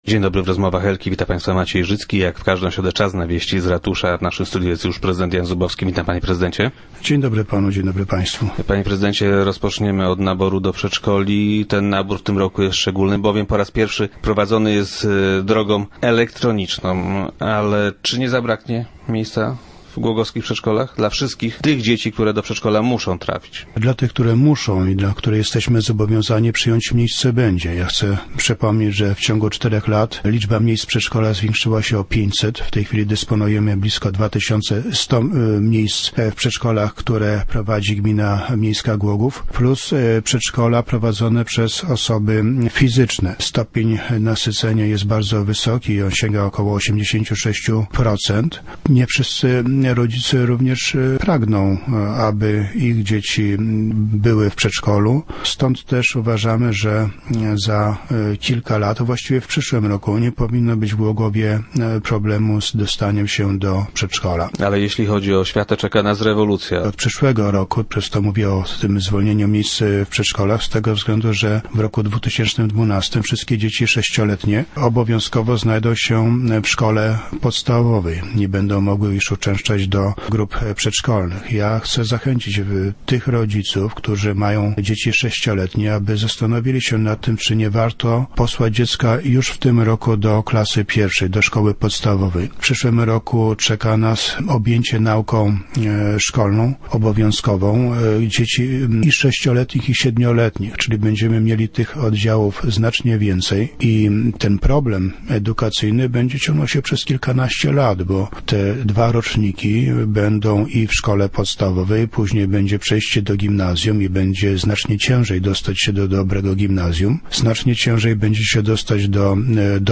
- W przyszłym roku nie powinno już być problemu z dostaniem się do przedszkola - twierdzi prezydent Jan Zubowski, który był dziś gościem Rozmów Elki.